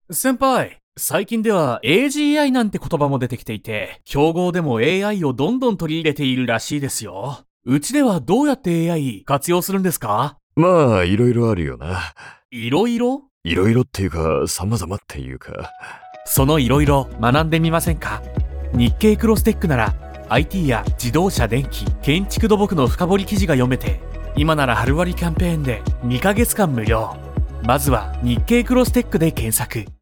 企業のリーダー層をターゲットに、上司と部下の"あるある"な会話を題材にしたストーリー仕立てのCMを制作。